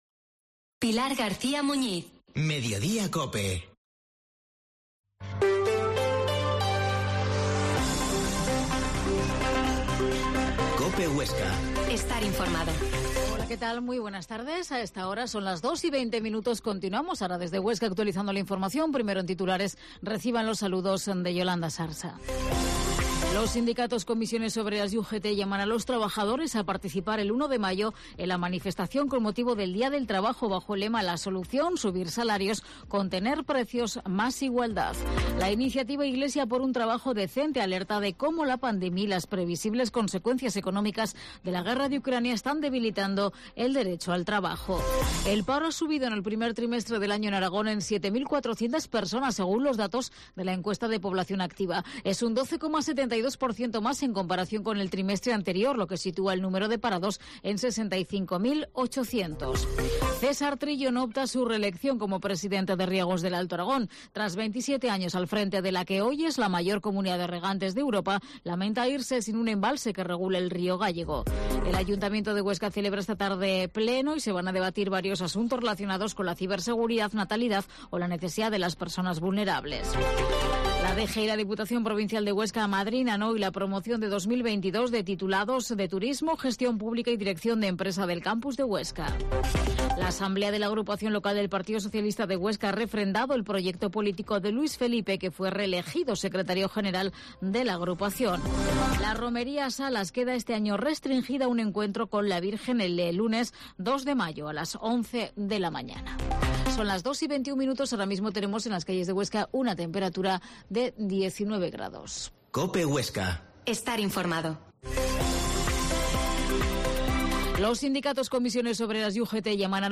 Informativo